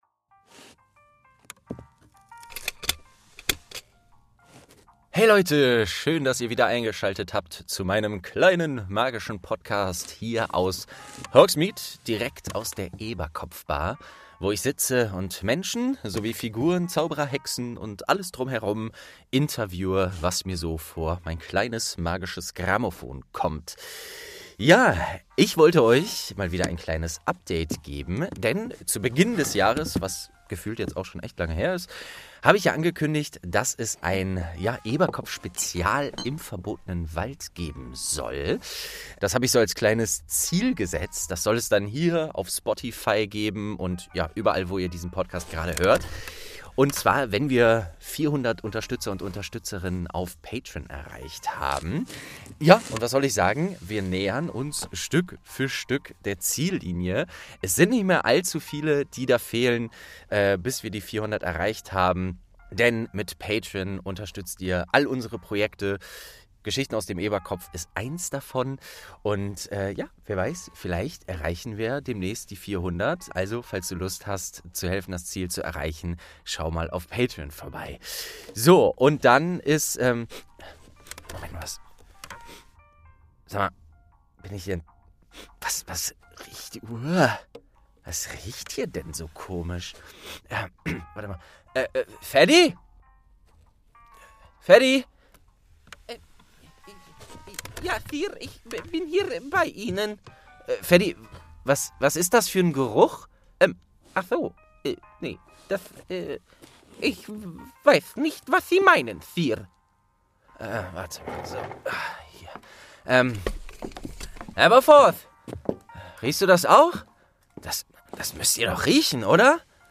29. Schleim-Monster und Auroren-Rettung| St. 2 ~ Geschichten aus dem Eberkopf - Ein Harry Potter Hörspiel-Podcast Podcast